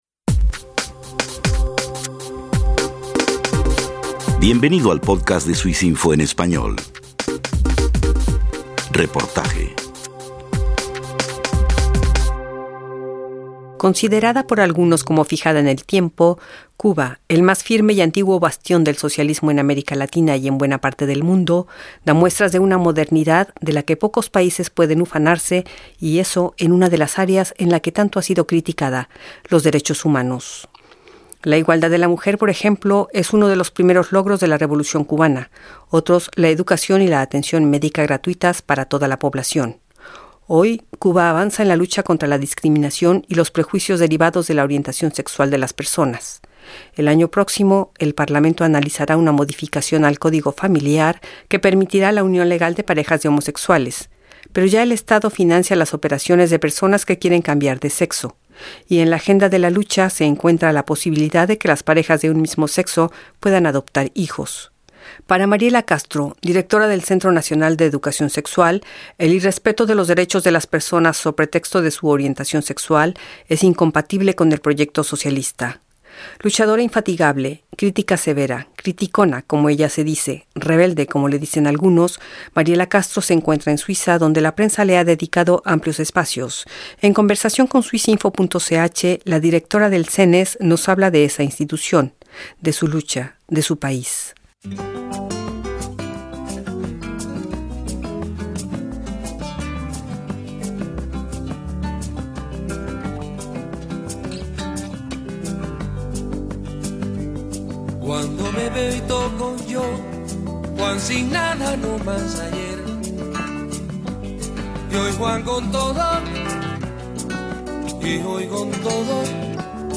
Entrevista con la directora del CENESEX cubano